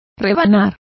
Complete with pronunciation of the translation of slicing.